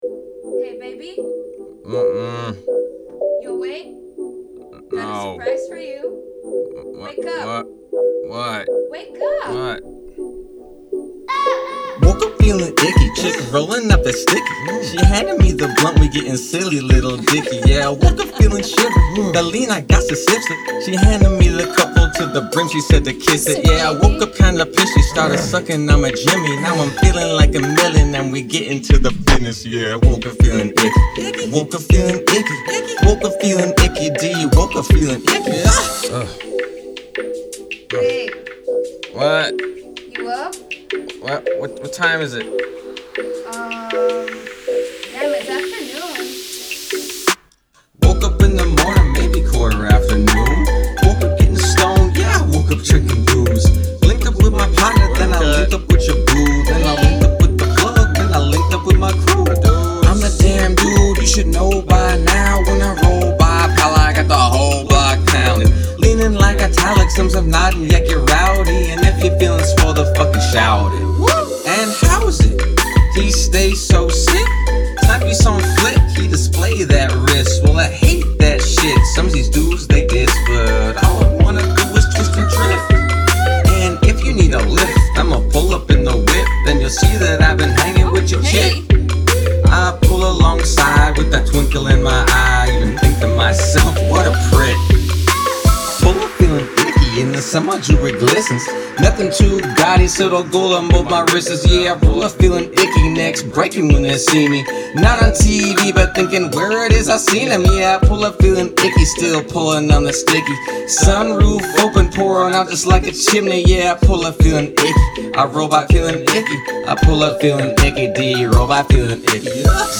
Hey Guys, I've posted once or twice before with similar issues - I am an aspiring rapper, but the mixing and mastering is much too tedious for me, I think...
I've just posted my latest track on this thread...I think it sounds decent but I know it needs better mixing/mastering. The lyrics don't stand out from the beat very well.